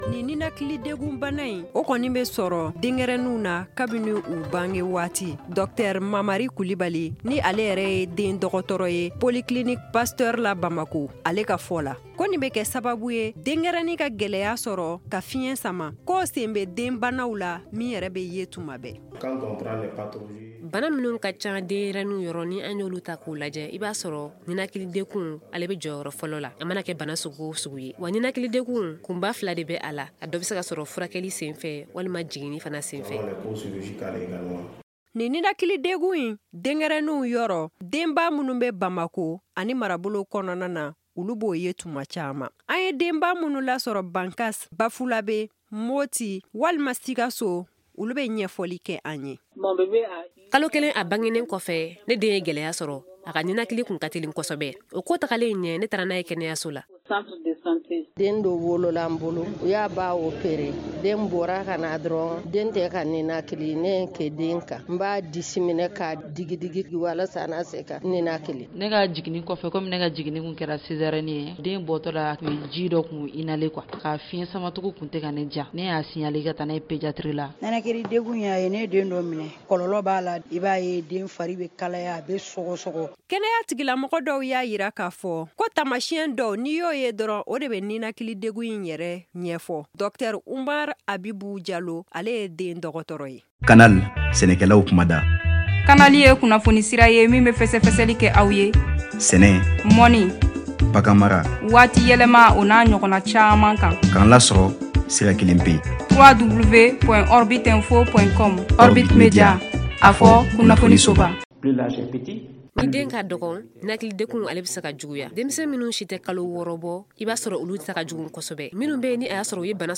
magazine santé